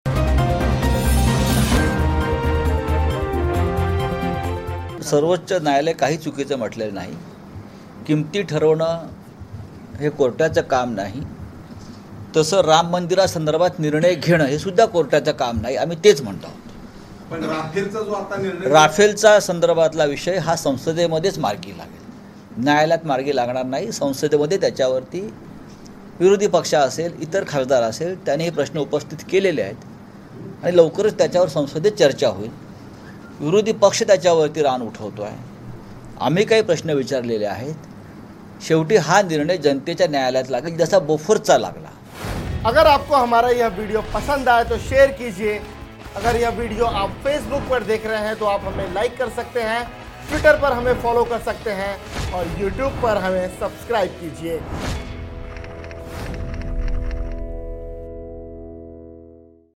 न्यूज़ रिपोर्ट - News Report Hindi / राफेल के फैसले पर बोले संजय राउत - राम मंदिर पर फैसला करना कोर्ट का काम नहीं